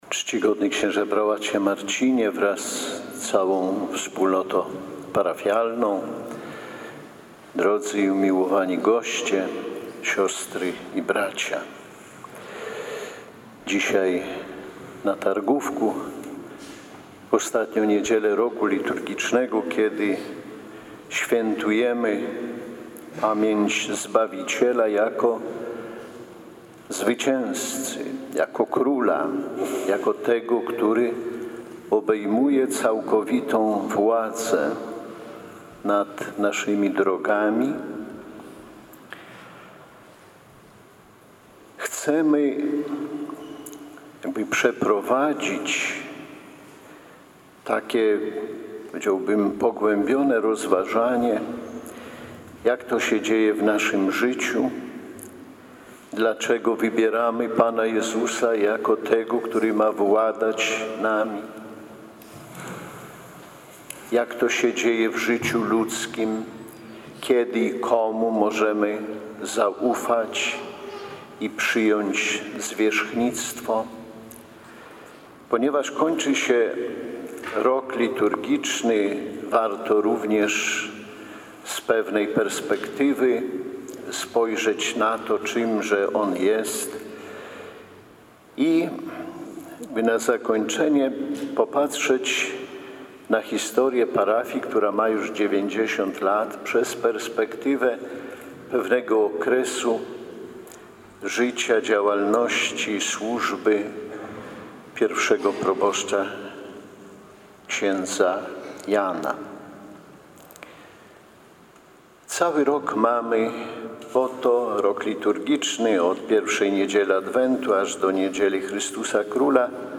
Ordynariusz warszawsko-praski bp Romuald Kamiński przewodniczył uroczystej Mszy Świętej w parafii Chrystusa Króla na Targówku.
Podczas homilii bp Romuald Kamiński mówił o szczególnych relacjach, które łączą człowieka z Bogiem.